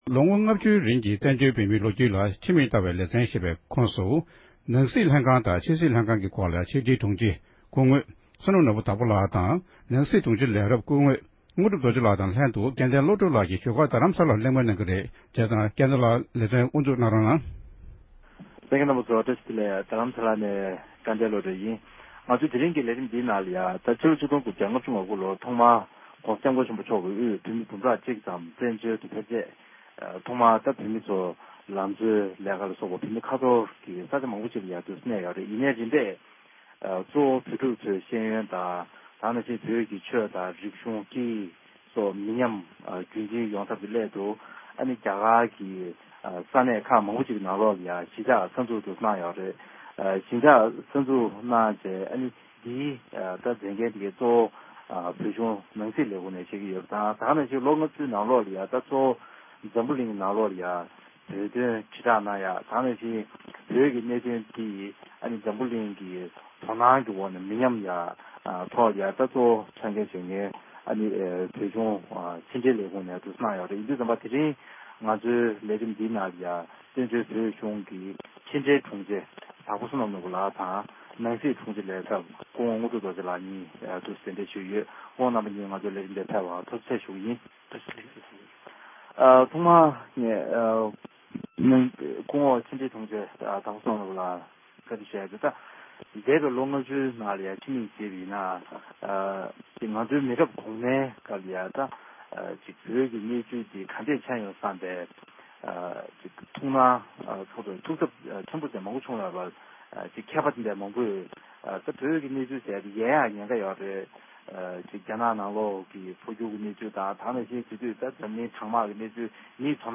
བཙན་བྱོལ་བོད་གཞུང་གི་ནང་སྲིད་དང་ཕྱི་སྲིད་ལྷན་ཁང་གི་ངོ་སྤྲོད་སྐོར་བགྲོ་གླེང་གནང་བ།